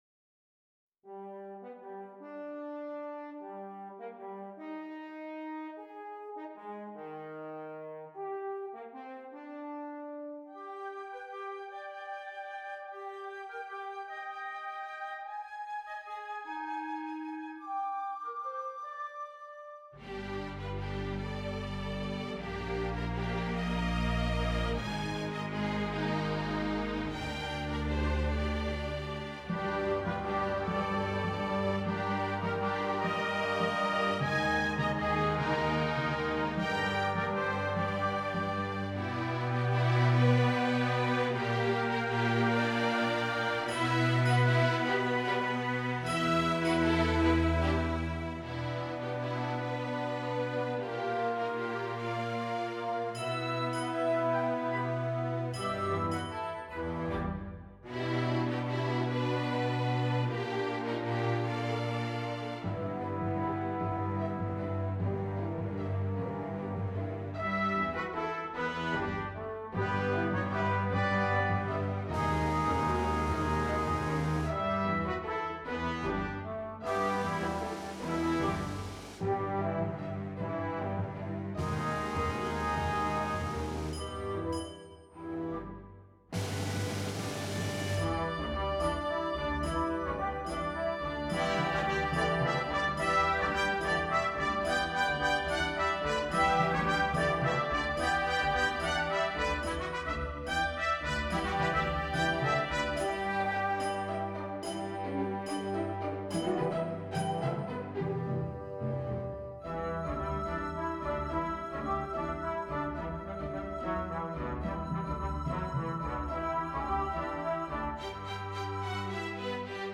New Orchestra Composition -